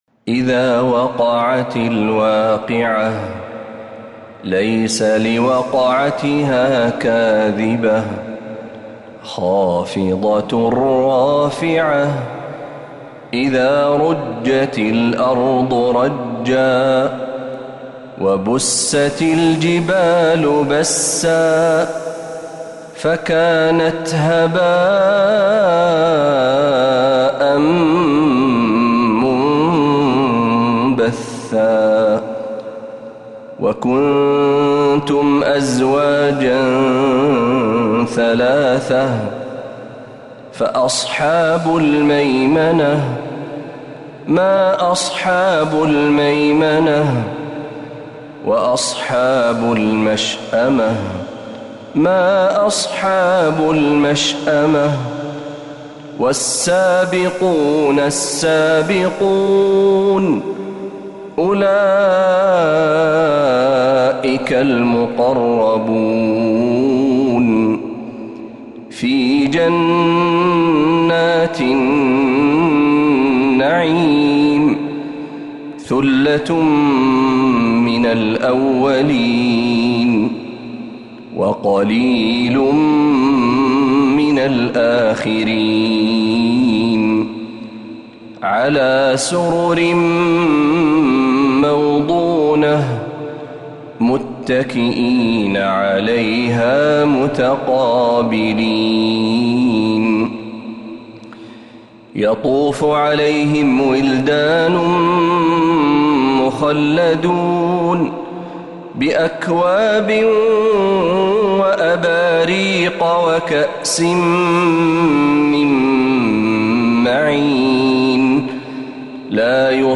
سورة الواقعة كاملة من الحرم النبوي